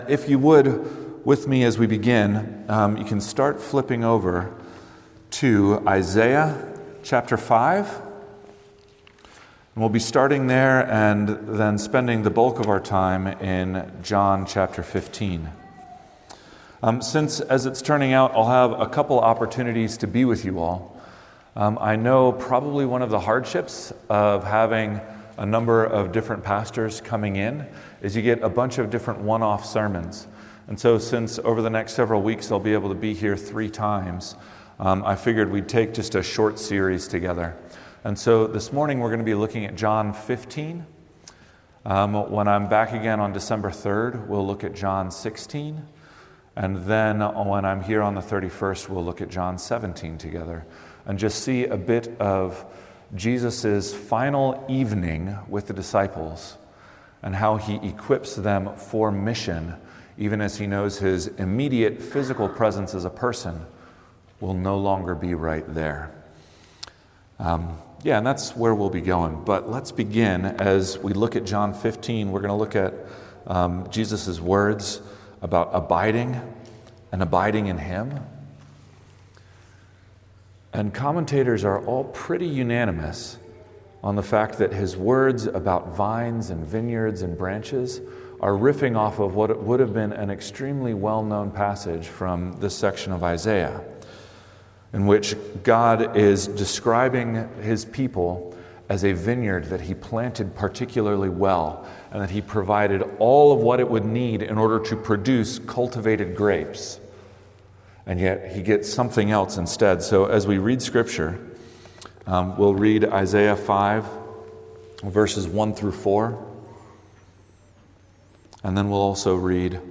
Sermons – Trinity Presbyterian Church
From Series: "Guest Sermons"